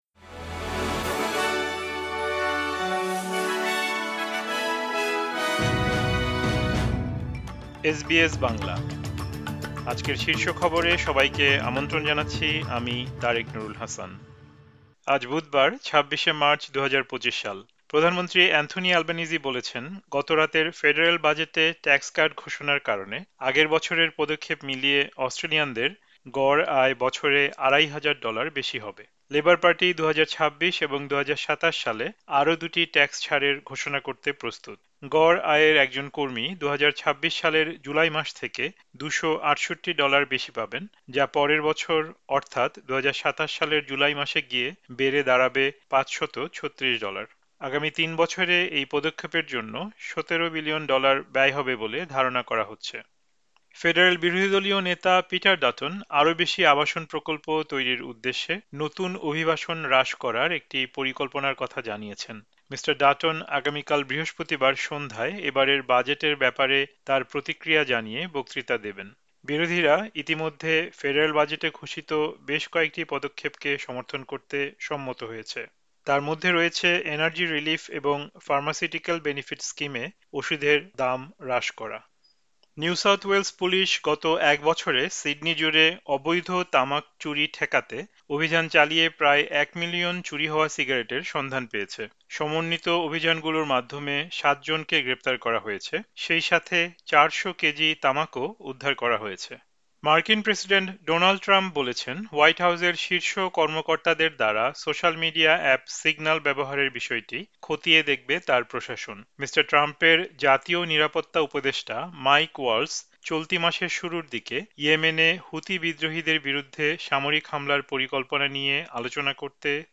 এসবিএস বাংলা শীর্ষ খবর: ২৬ মার্চ, ২০২৫